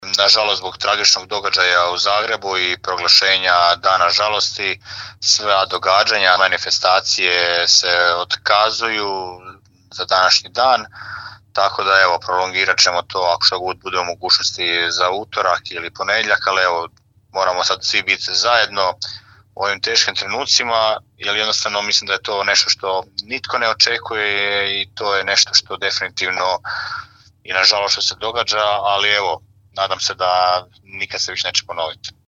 Osim zastava spuštenih na pola koplja u našem malom gradu ove subote otkazani su svi pripremljeni programi i manifestacije. Poslušajmo gradonačelnika Daruvara Damira Lneničeka: